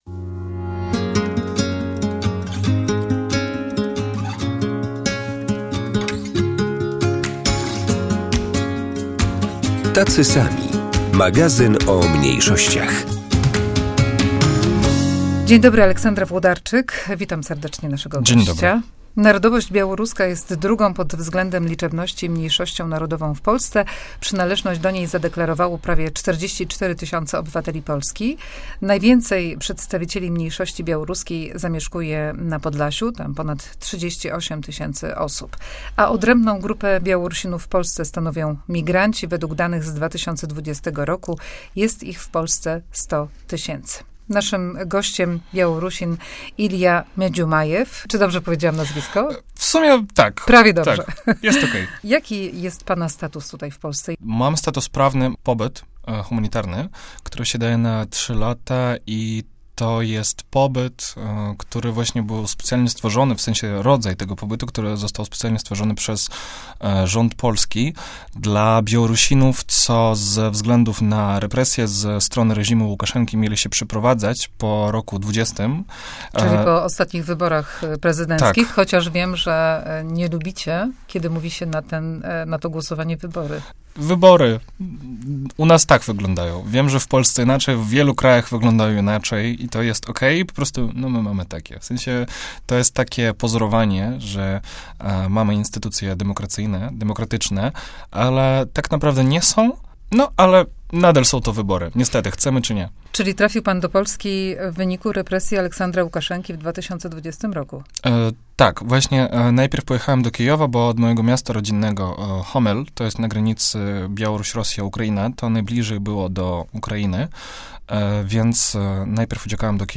Do Polski trafił po protestach w 2020 roku, kiedy tysiące Białorusinów protestowało przeciwko sfałszowanym wyborom prezydenckim. Rozmowa o ucieczce z kraju, życiu w Polsce, o trudnościach, losach i kulturze. audycja